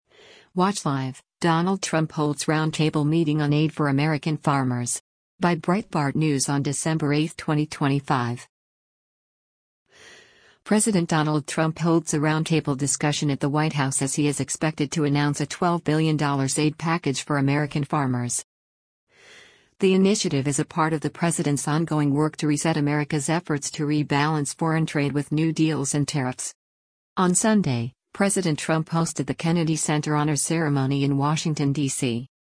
President Donald Trump holds a roundtable discussion at the White House as he is expected to announce a $12 billion aid package for American farmers.